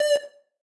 synth3_11.ogg